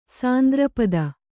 ♪ sandra pada